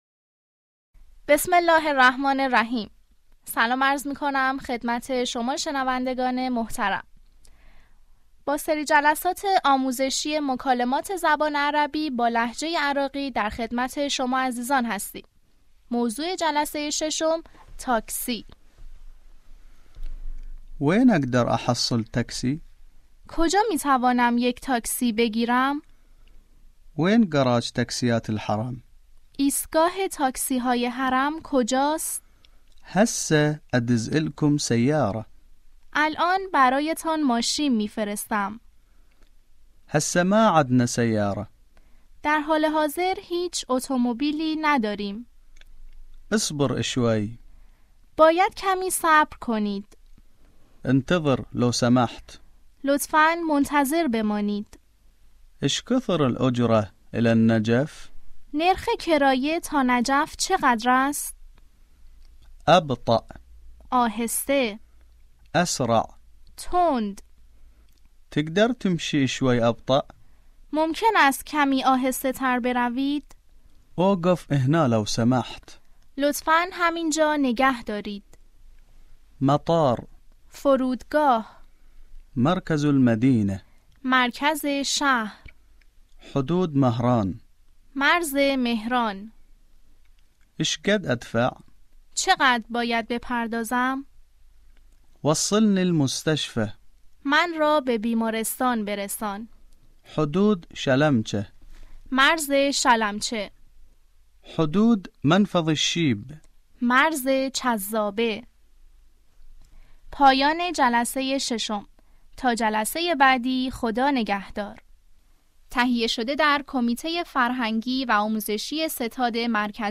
آموزش مکالمه عربی به لهجه عراقی